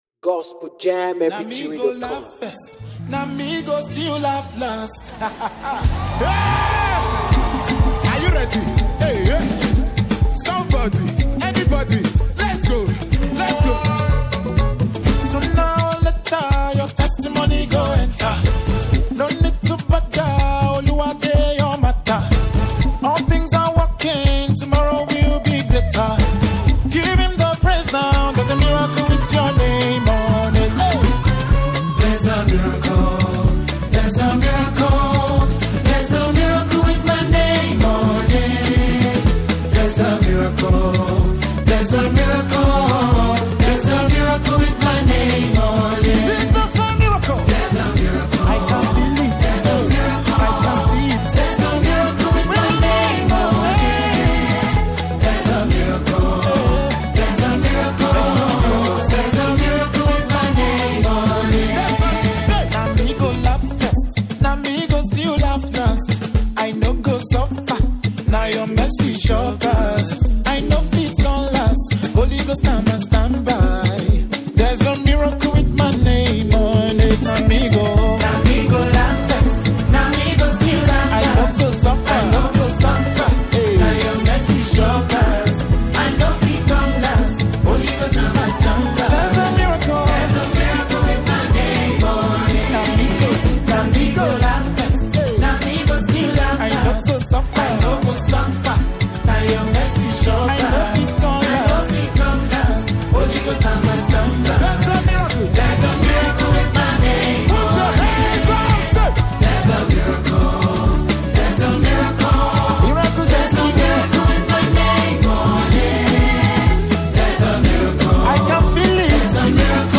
spiritually uplifting gospel worship song